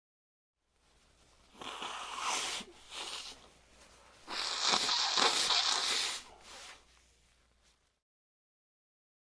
人的声音 吹鼻子的声音效果 免费的高质量的声音效果
描述：人类的声音吹鼻子声音效果免费高品质声音效果